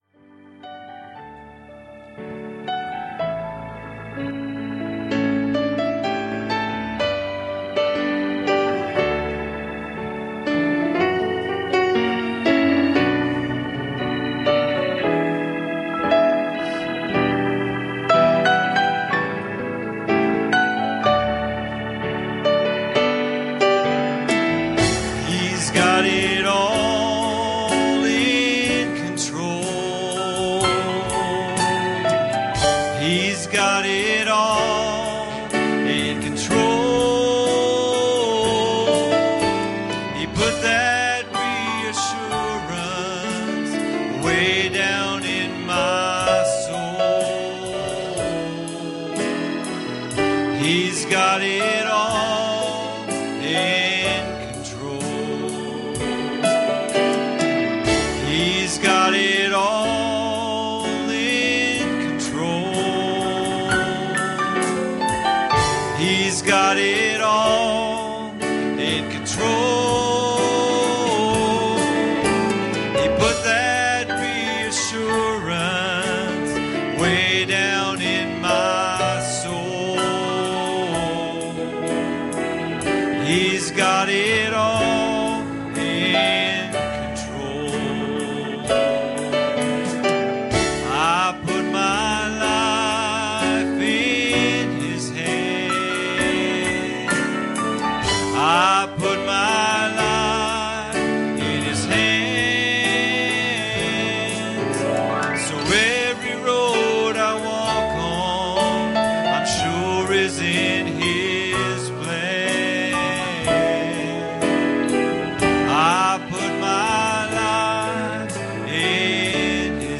Passage: John 1:19 Service Type: Wednesday Evening